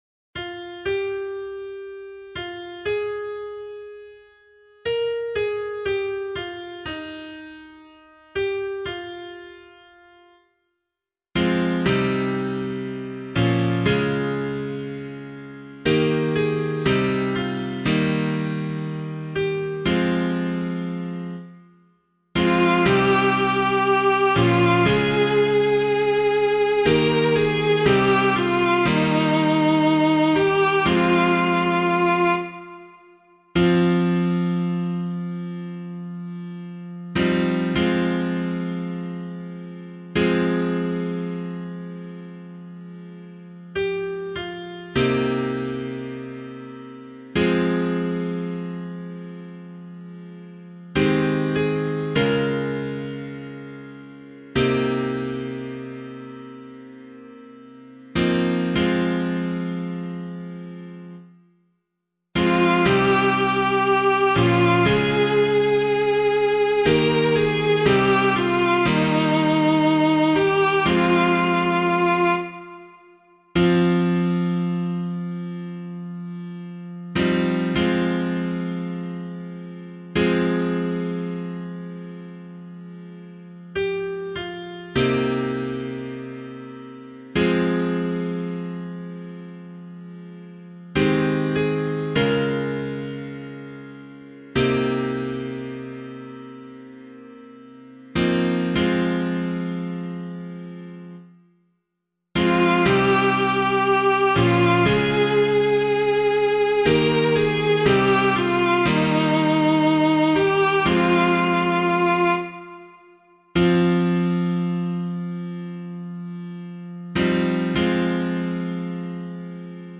018 Palm Sunday Psalm [APC - LiturgyShare + Meinrad 2] - piano.mp3